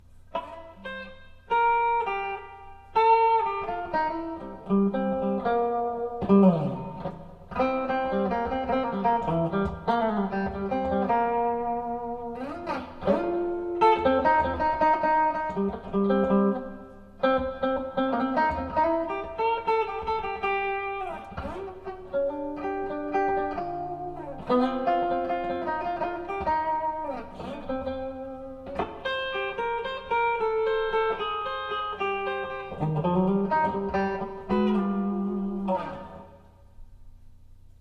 Electric Guitar | Photos and Recordings
496ROC Guitar | Recorded with 30W Transistor Amp (10" Speaker) and Yamaha PA